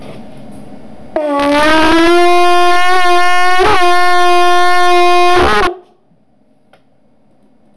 Trombe fatte di conchiglie
tromba di conchiglia 1.wav